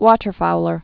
(wôtər-foulər, wŏtər-)